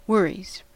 Ääntäminen
Ääntäminen US RP : IPA : /ˈwʌriz/ Haettu sana löytyi näillä lähdekielillä: englanti Käännöksiä ei löytynyt valitulle kohdekielelle. Worries on sanan worry monikko.